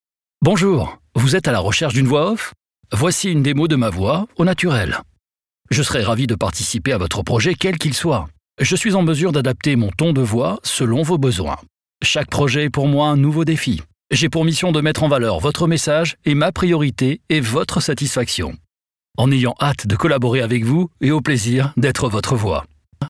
NEUTRE